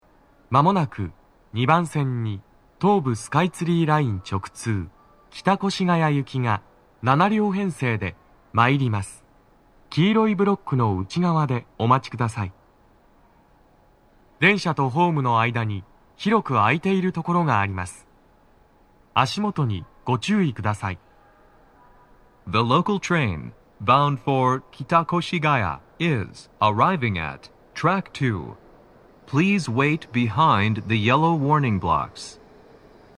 スピーカー種類 TOA天井型
鳴動は、やや遅めです。
男声